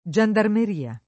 Jendarmer&a] (dove peraltro, mancando gli usi fig., la var. giandarmeria [